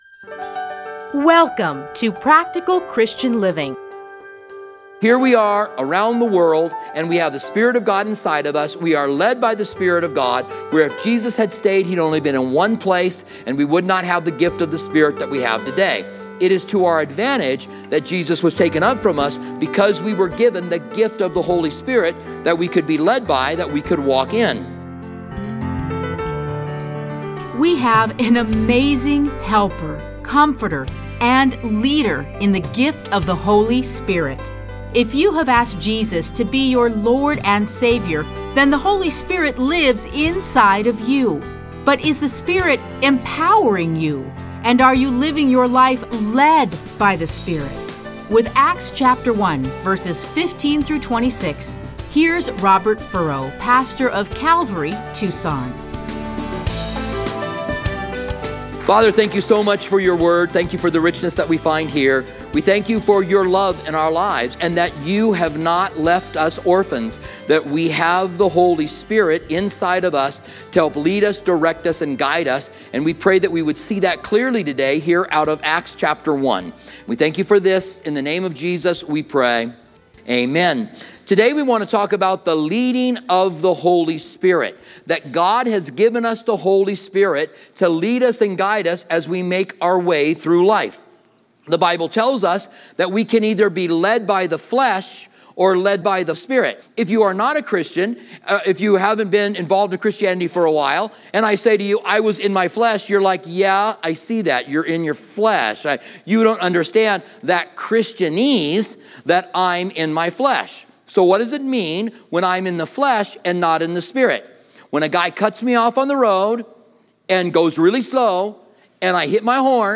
Listen to a teaching from Acts 1:15-26.